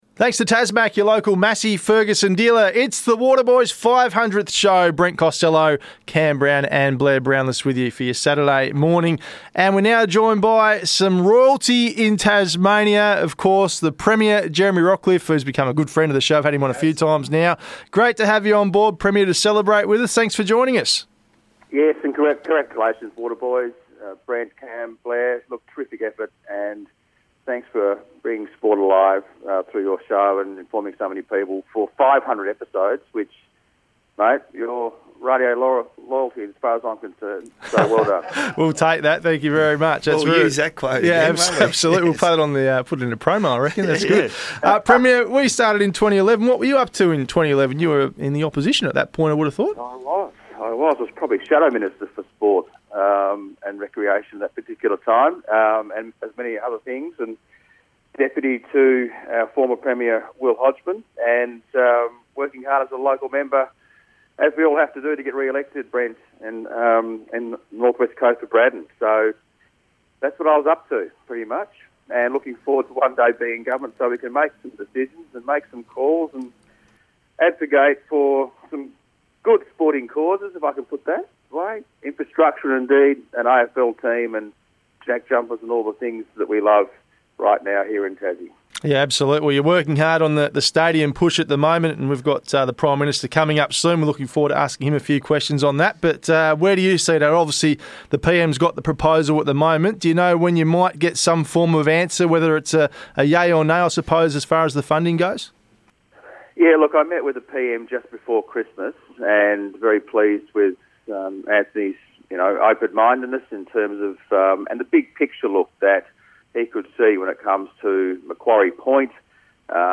Jeremy Rockliff Interview - January 21st 2023